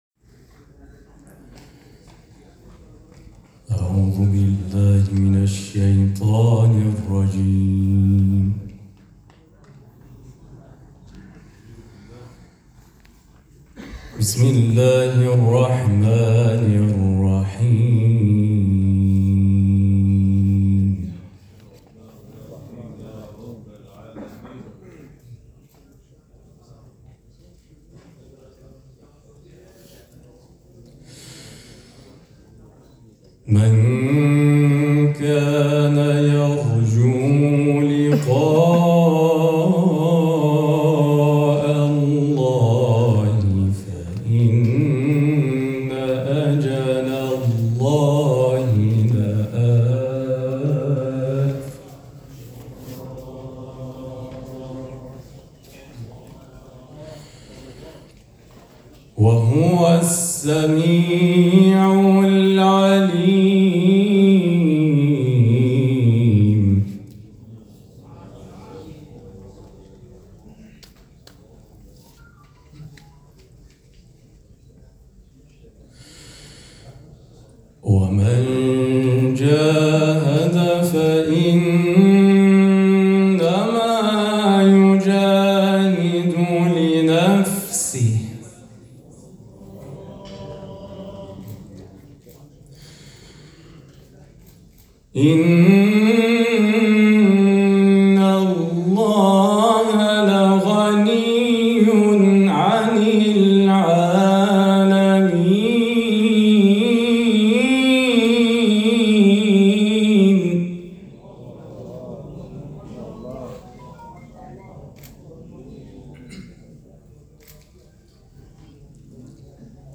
چهل و پنجمین دوره مسابقات سراسری قرآن